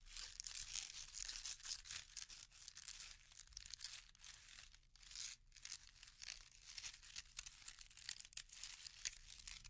衣服沙沙响
描述：连衣裙福莱聚酯纤维面料
Tag: 面料 沙沙声 礼服 polyest呃